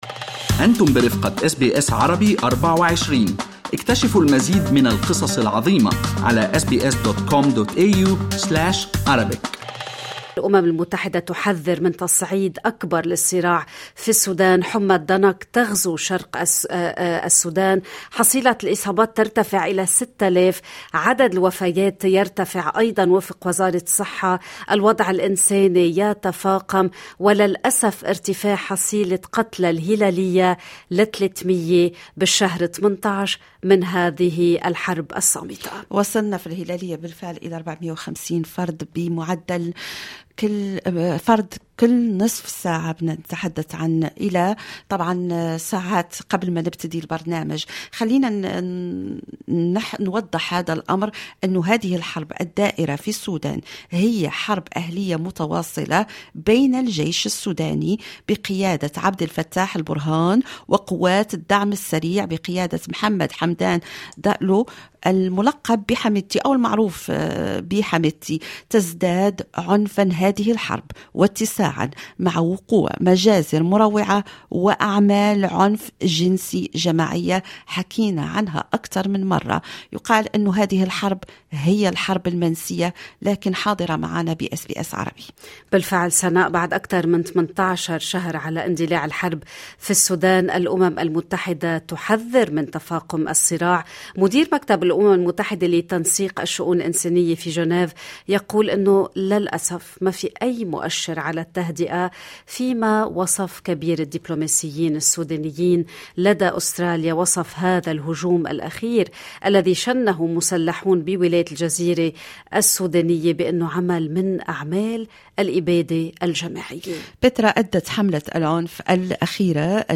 محلل سوداني: معظم ضحايا الحرب في السودان من المدنيين والأطفال المجندين